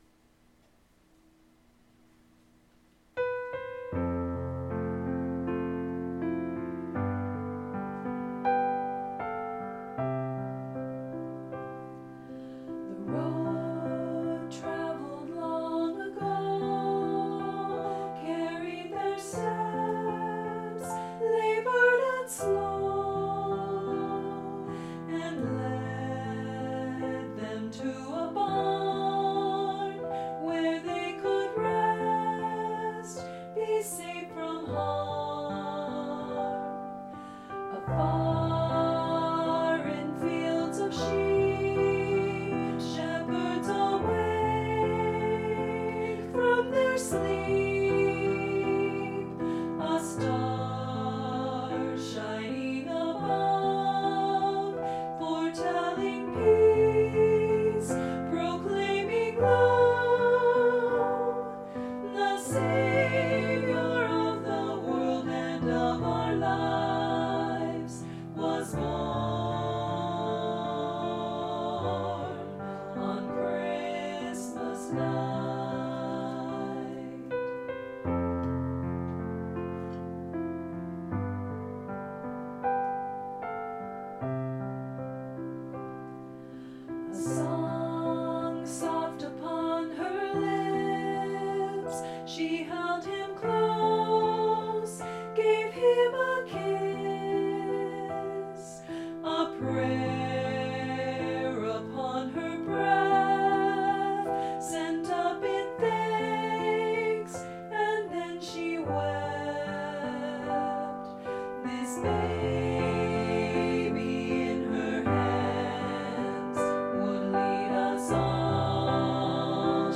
SAA, SSA, Trio
Voicing/Instrumentation: SAA , SSA , Guitar Chords Available , Trio